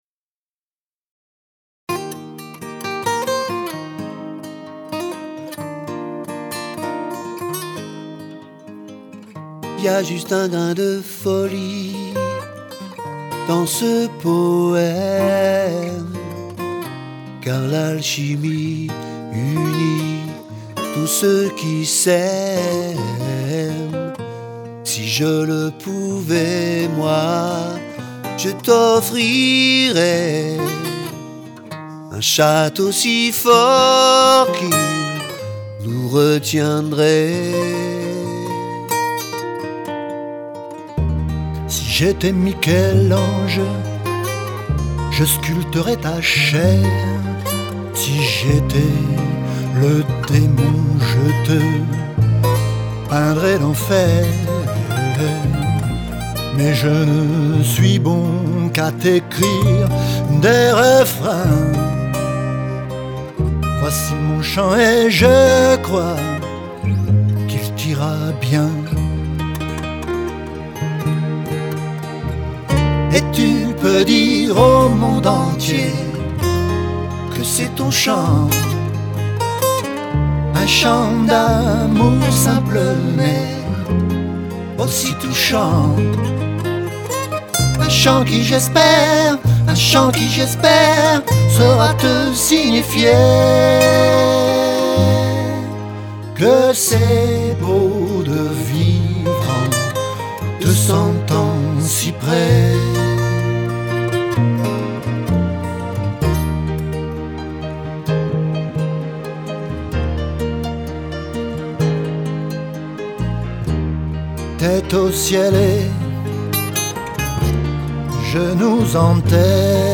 au chant
guitares